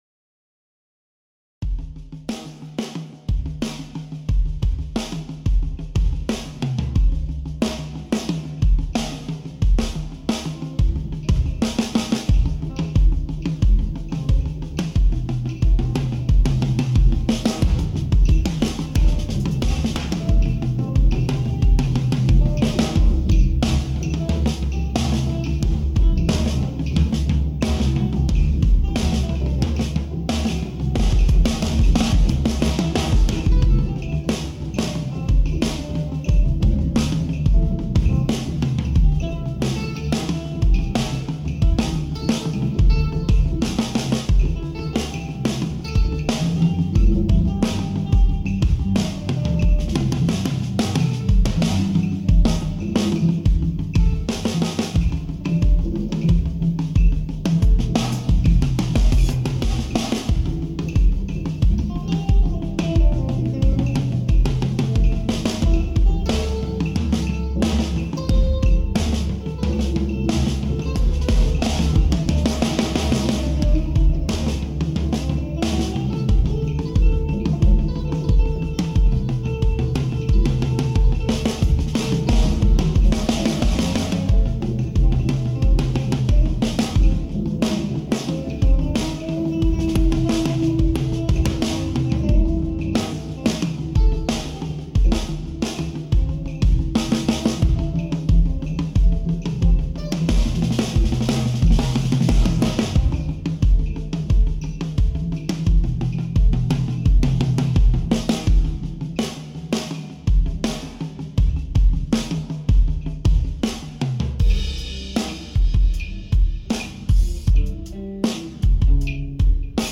drums, perc, ac-guitar, e-bass, keyboard, voice
recorded with Lexicon Omega and Cubase4